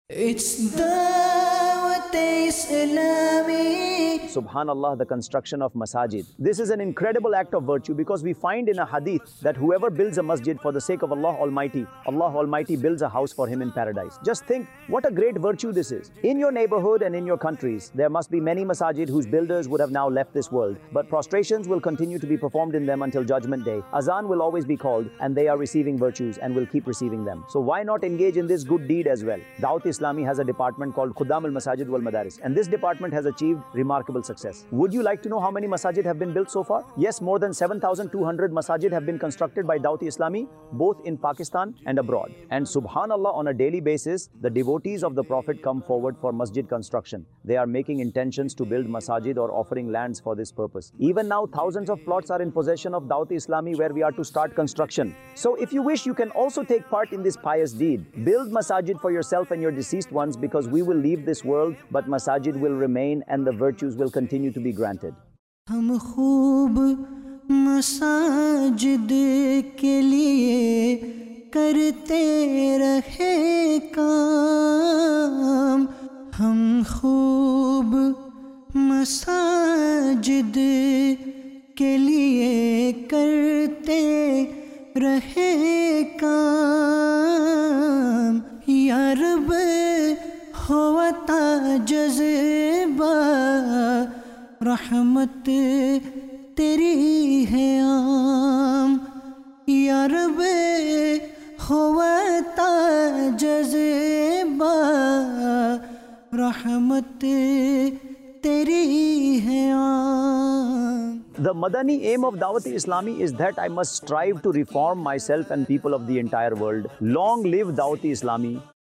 Khuddam-ul-Masajid Wal Madaris-ul-Madinah | Department of Dawateislami | Documentary 2025 | AI Generated Audio Mar 22, 2025 MP3 MP4 MP3 Share خدّام المساجد والمدارس المدینہ | شعبہِ دعوت اسلامی | ڈاکیومینٹری 2025 | اے آئی جنریٹڈ آڈیو